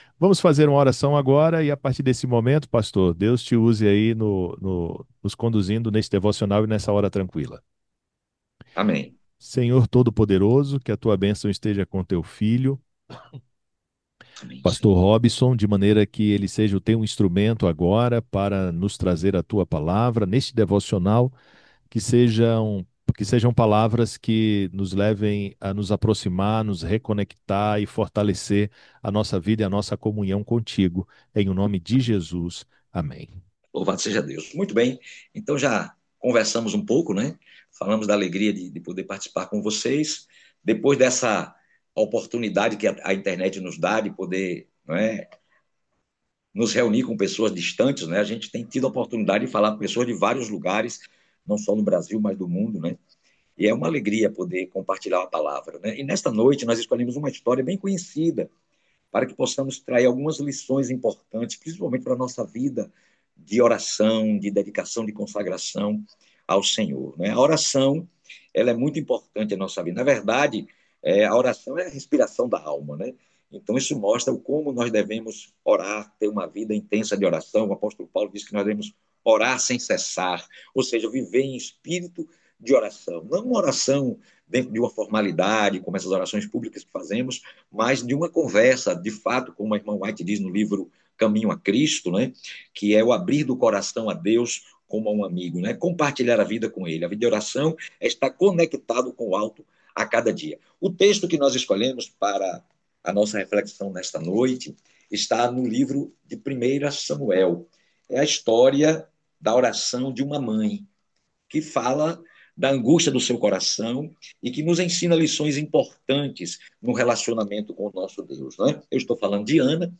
A Hora Tranquila é um devocional semanal.
Devocional.mp3